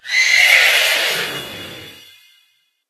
Cri de Blizzeval dans Pokémon HOME.